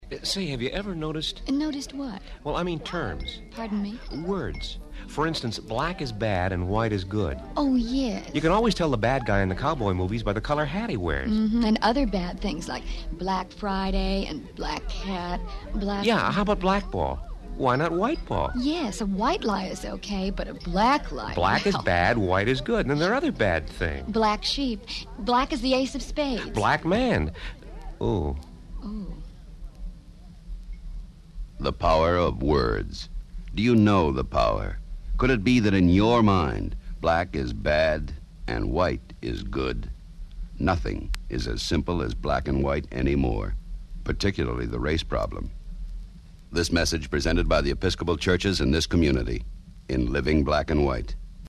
"Free, White and 21," a public service radio announcement produced by the Executive Council of the Episcopal Church, reminds listeners of the true meaning behind an often-repeated phrase.